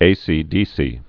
(āsē-dēsē)